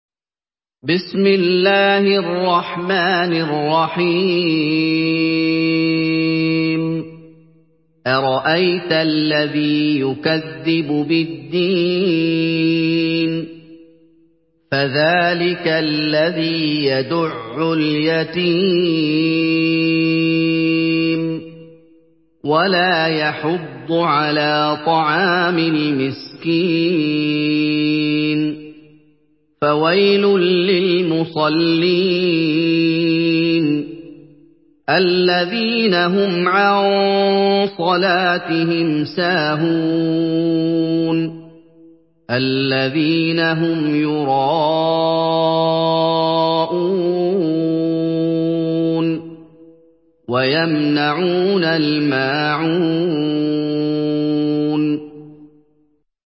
Surah Al-Maun MP3 by Muhammad Ayoub in Hafs An Asim narration.
Murattal